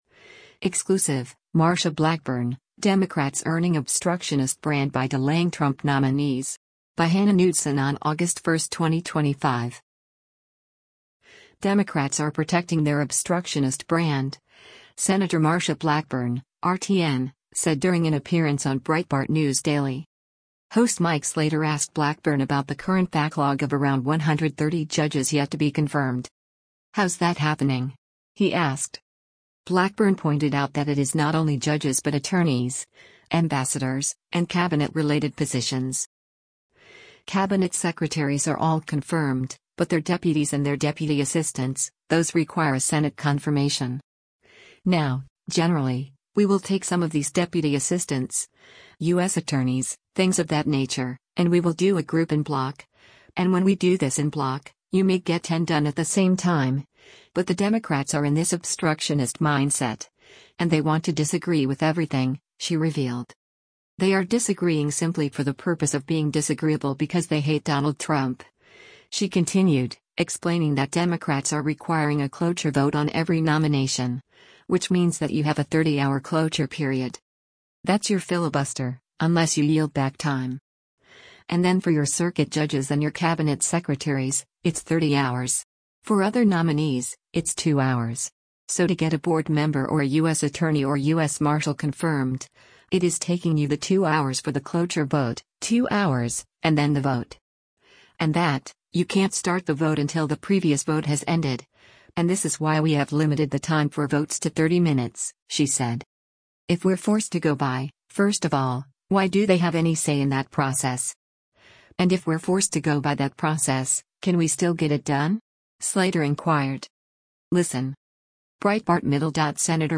Democrats are protecting their “obstructionist brand,” Sen. Marsha Blackburn (R-TN) said during an appearance on Breitbart News Daily.
Breitbart News Daily airs on SiriusXM Patriot 125 from 6:00 a.m. to 9:00 a.m. Eastern.